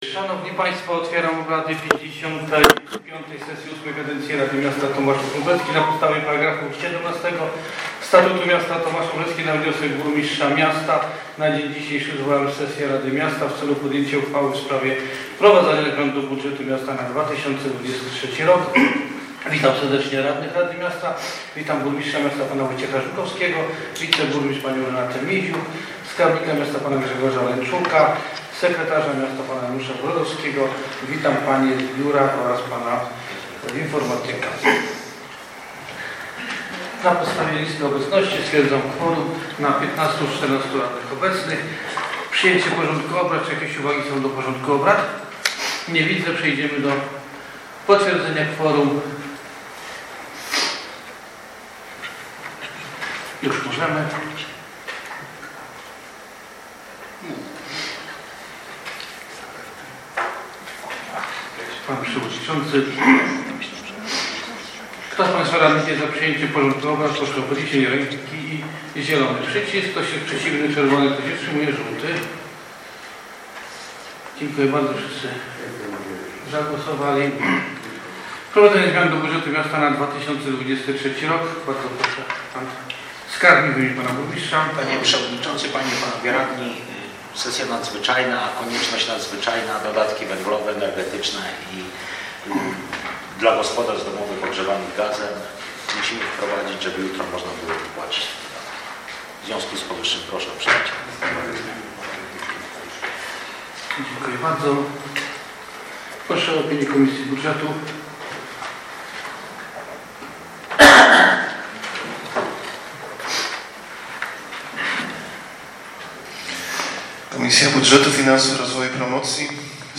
Nagranie z posiedzenia
LV sesja Rady Miasta w dniu 30 stycznia 2023, godz. 08:00, w sali konferencyjnej Urzędu Miasta, przy ul. Lwowskiej 57